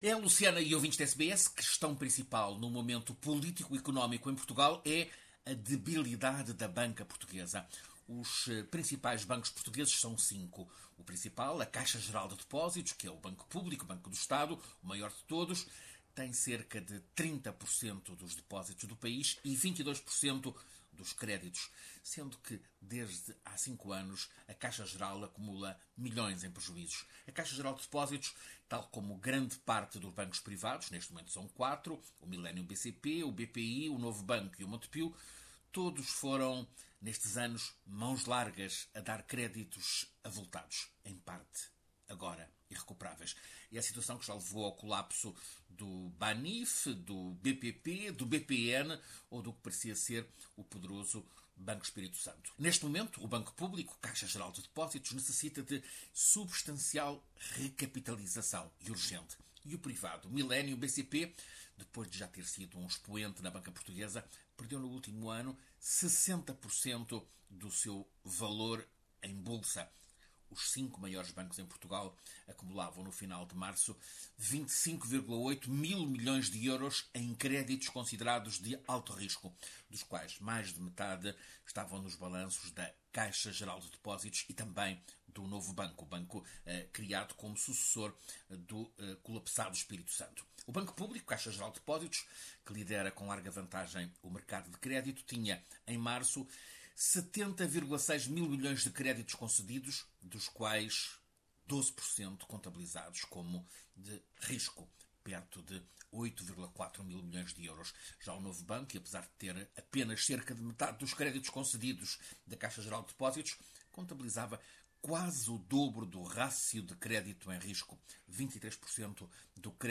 O privado Millennium, o maior dos privados, perdeu num ano 60% do seu valor. Créditos concedidos sem a necessária exigência e efeitos da crise econômica seriam as razões da desvalorização. Ouça reportagem